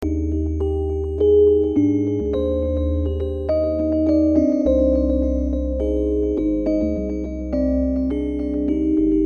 标签： 104 bpm Chill Out Loops Organ Loops 1.55 MB wav Key : Unknown
声道立体声